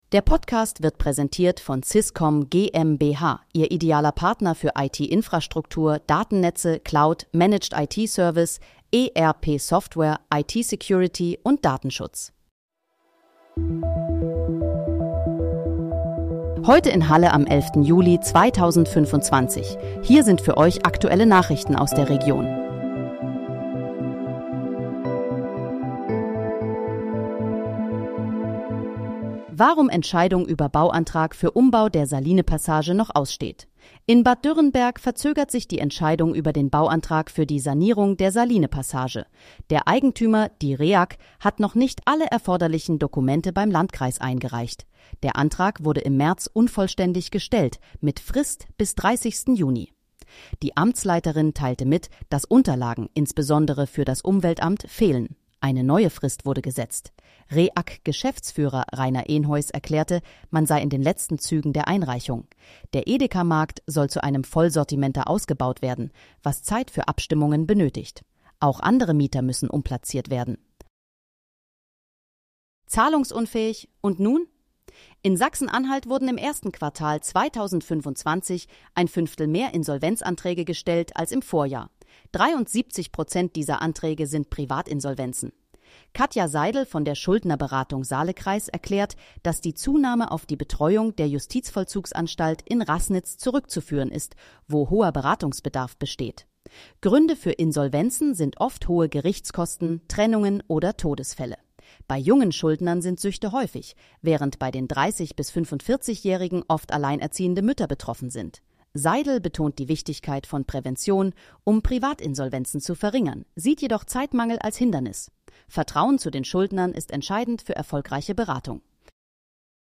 Heute in, Halle: Aktuelle Nachrichten vom 11.07.2025, erstellt mit KI-Unterstützung
Nachrichten